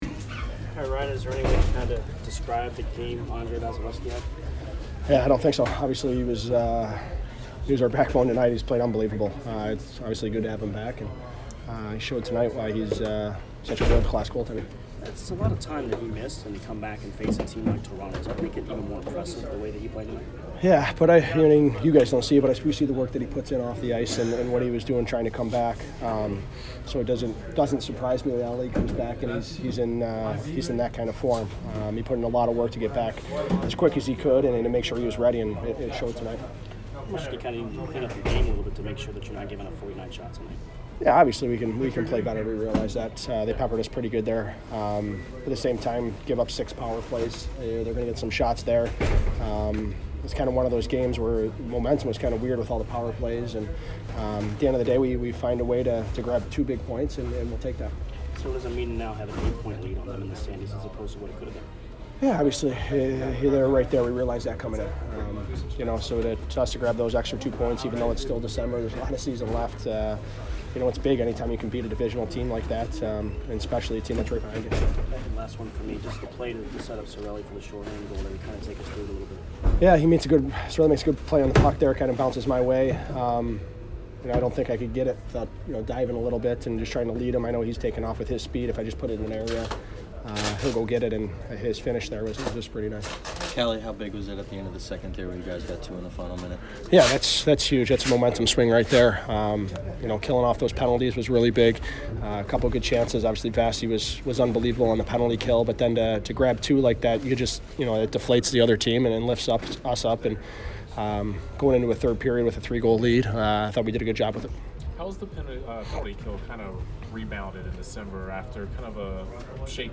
Ryan Callahan post-game 12/13